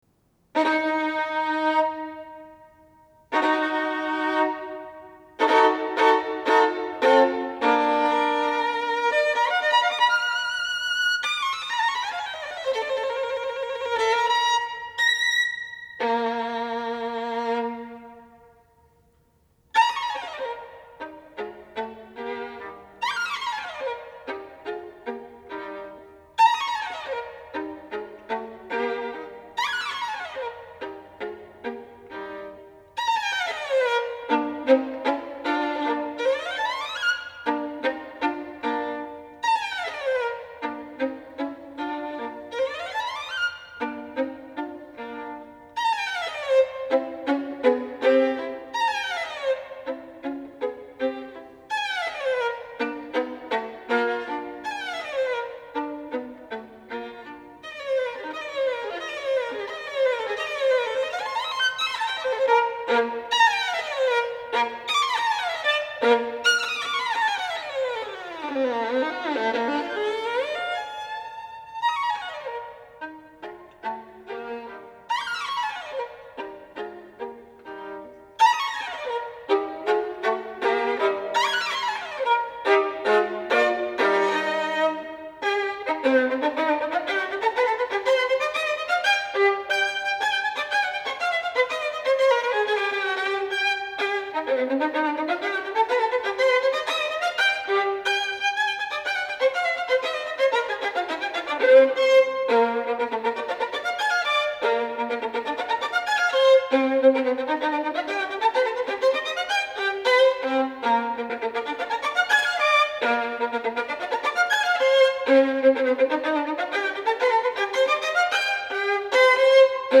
Слушание Каприс № 17, ми бемоль мажор, Паганини.